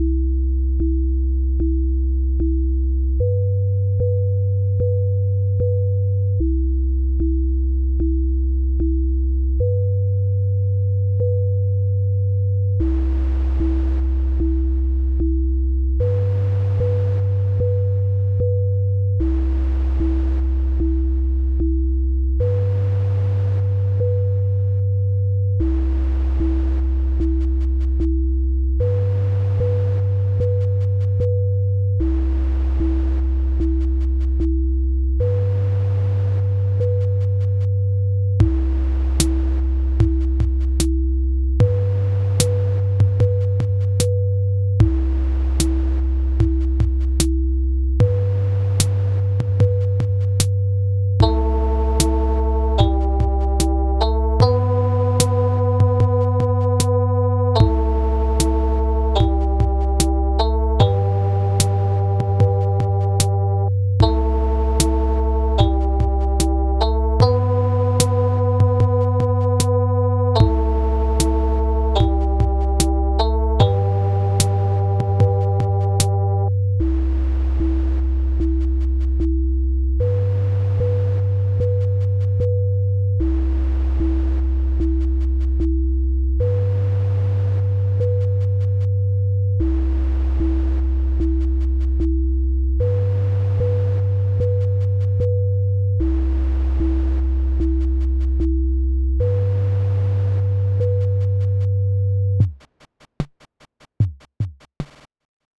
Bucle de música Ambient
Música electrónica
ambiente
melodía
repetitivo
rítmico
sintetizador